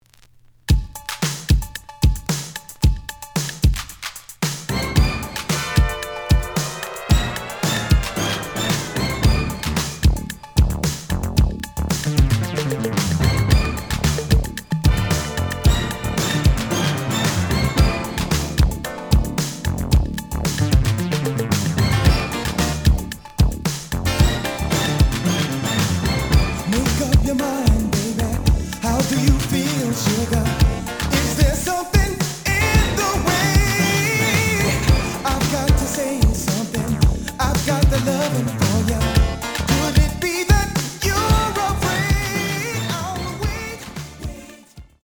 The audio sample is recorded from the actual item.
●Genre: House / Techno